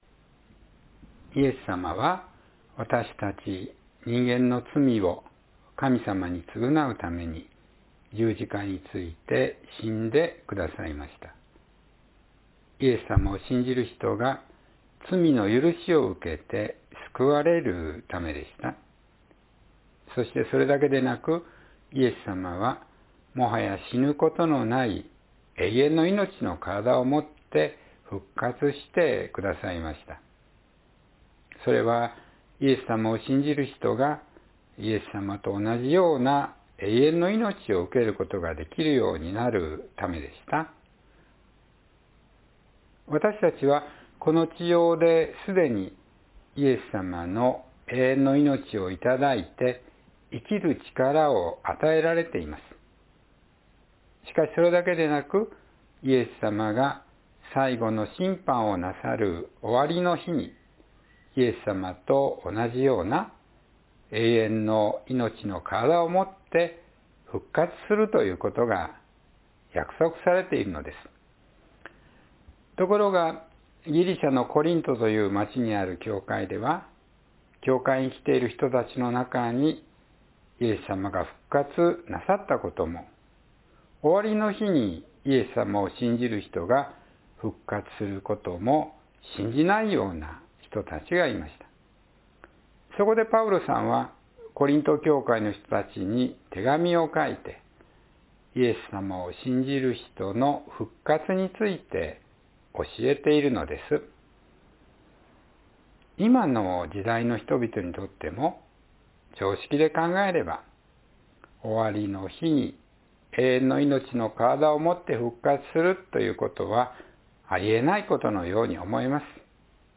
イエスさまを信じる人の復活（2026年5月3日・子ども説教）